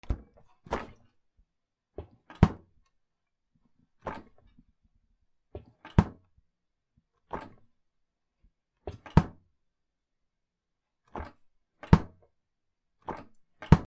冷蔵庫の開け閉め